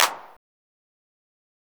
nightcore-clap.wav